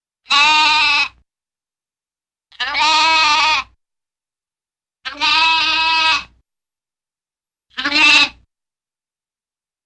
Звук блеяния маленького ягненка